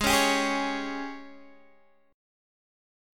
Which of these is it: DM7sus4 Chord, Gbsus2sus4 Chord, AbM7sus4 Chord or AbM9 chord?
AbM7sus4 Chord